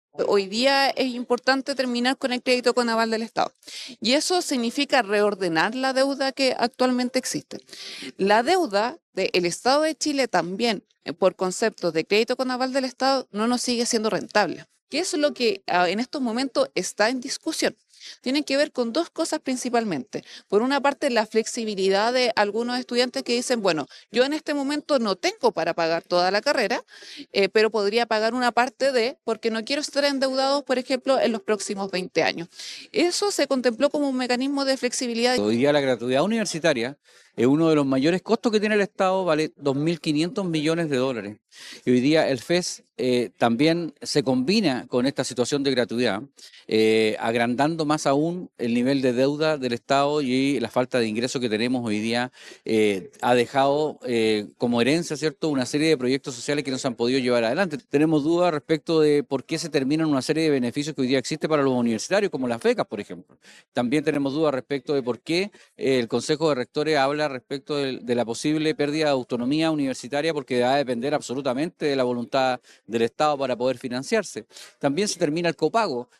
La diputada Daniela Serrano dio cuenta de las ventajas del proyecto al terminar con el CAE, mientras que el diputado de oposición, Frank Sauerbaum, indicó que la propuesta presidencial termina con las becas y el copago arancelario de las carreras.